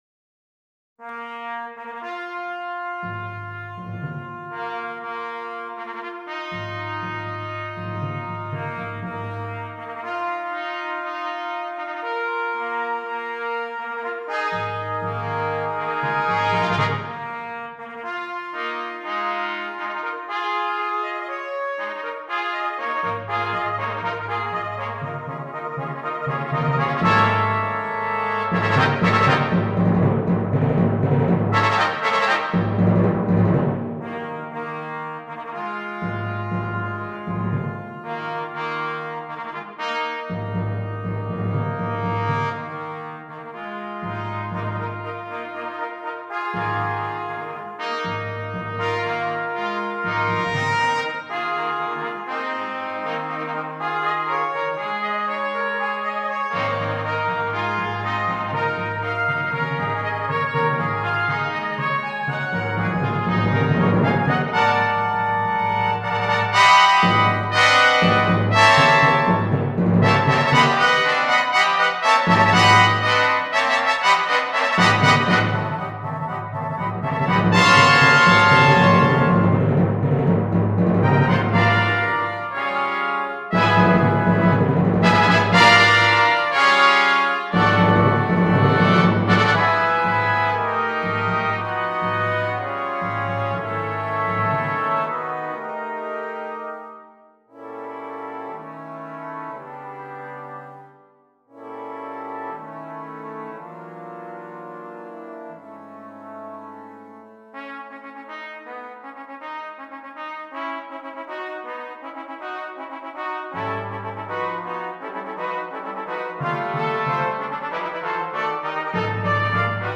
Brass
4 Trumpets, Bass Trumpet and Timpani